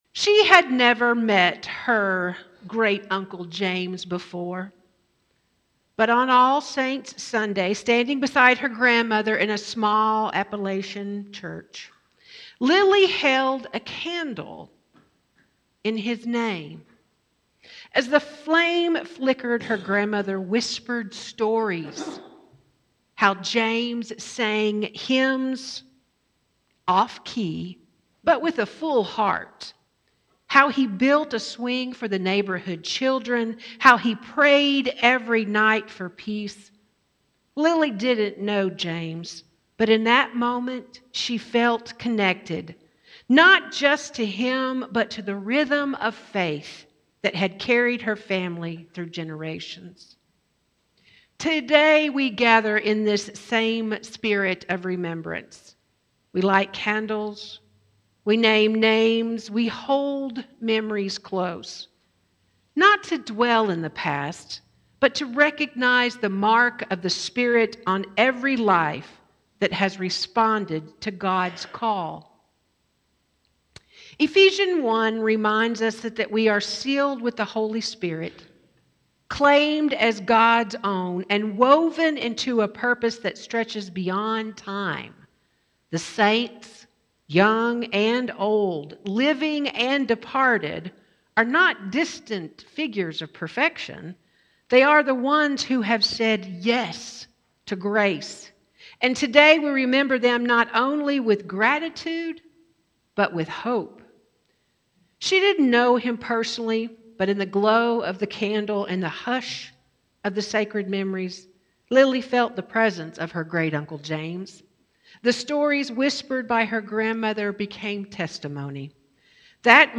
The sermon Marked by the Spirit invites us to reflect on Ephesians 1:11-23, where Paul speaks of divine inheritance, spiritual power, and the sealing of believers with the Holy Spirit.